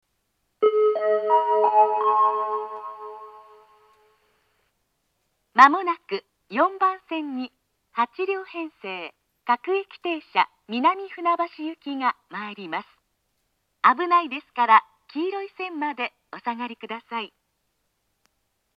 ４番線接近放送 各駅停車南船橋行の放送です。